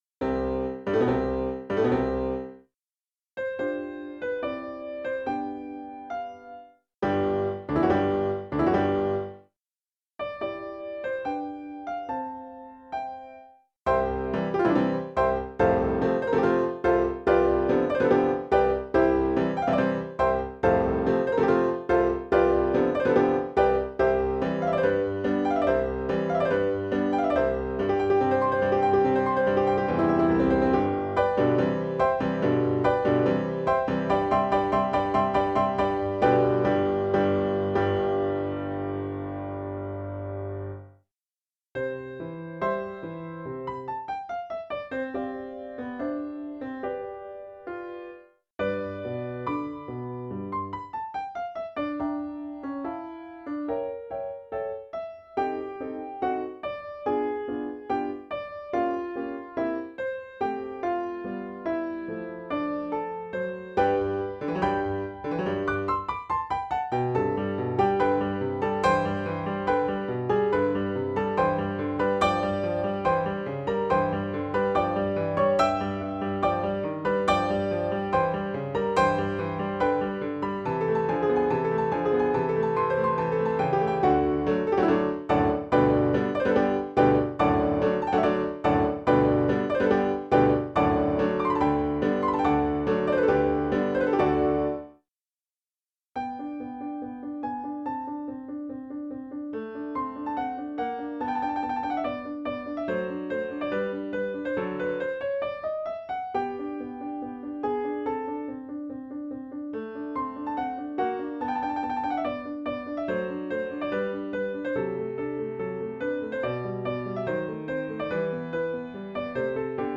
Allegro (2:54) Symphonies Symphony No. 41 in C Major, K. 551 (�Jupiter�) (arr. piano Hummel) 1. Allegro vivace (12:32) 2.